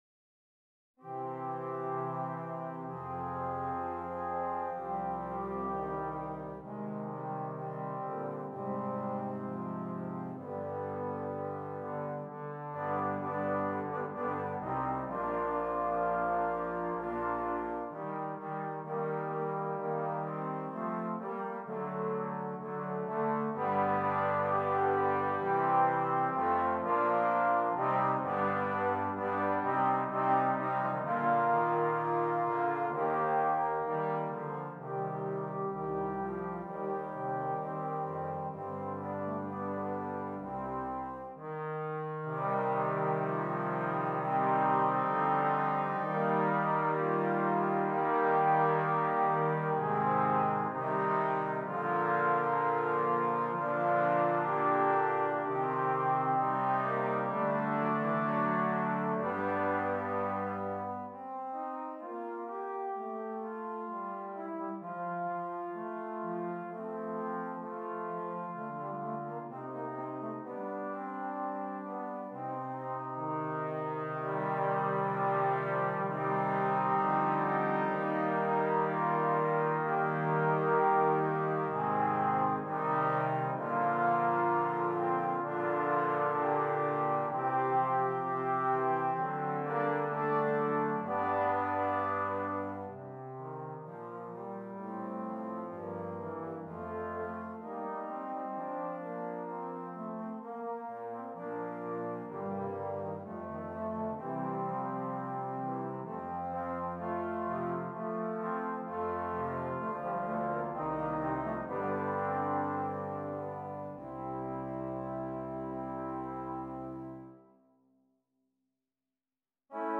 5 Trombones